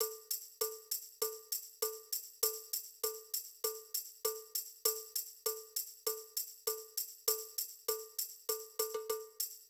PerQ à 99
GuiTrManPerQ.wav